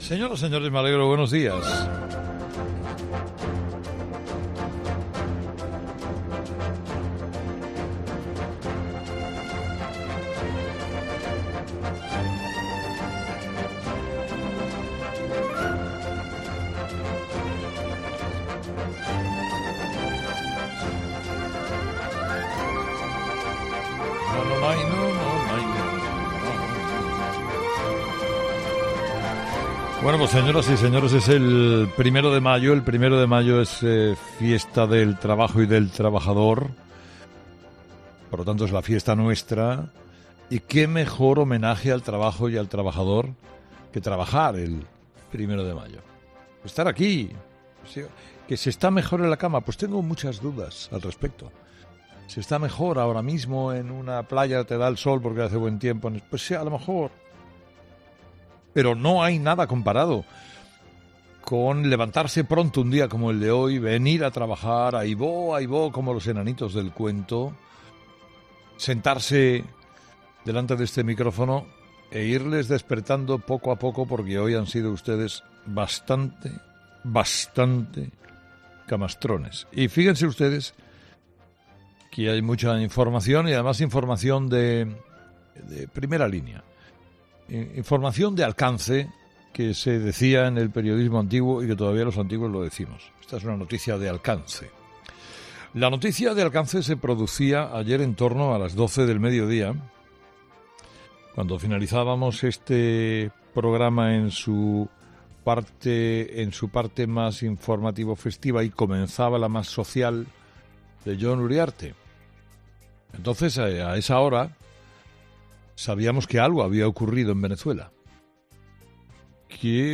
El comunicador analiza en su monólogo de este miércoles la difícil situación en Venezuela